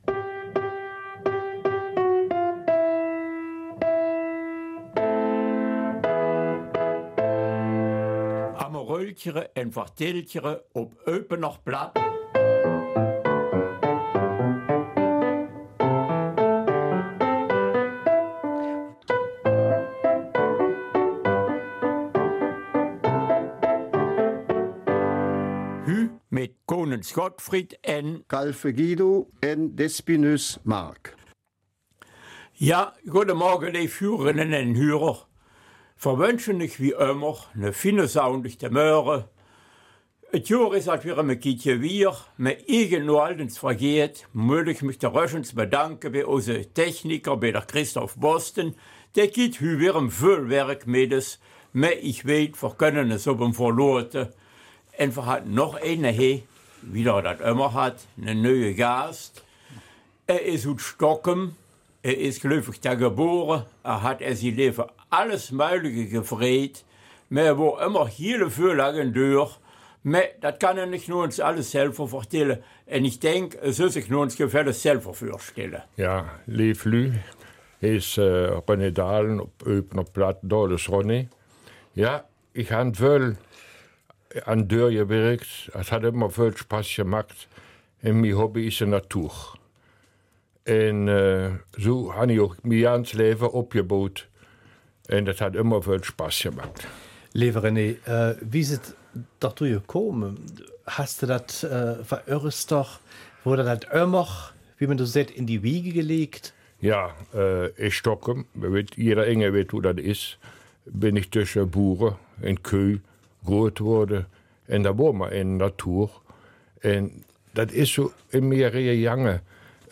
Eupener Mundart: Mit der Natur im Einklang